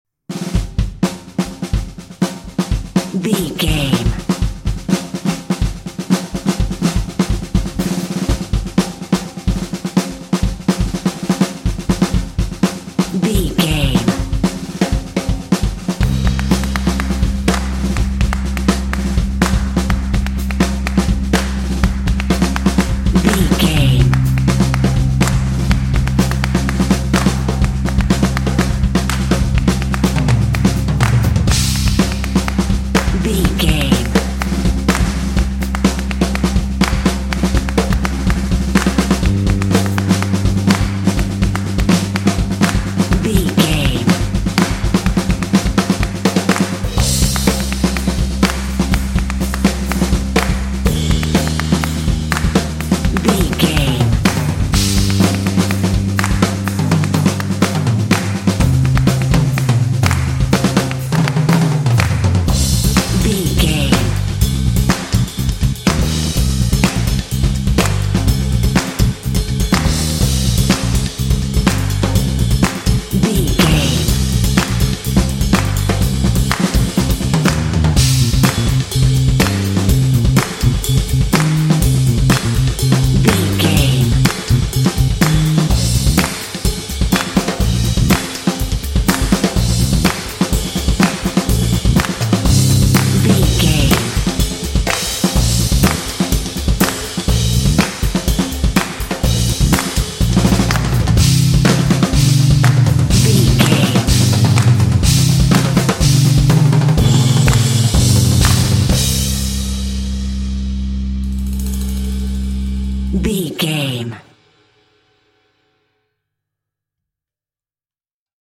Fast paced
Aeolian/Minor
energetic
bass guitar
drumline
contemporary underscore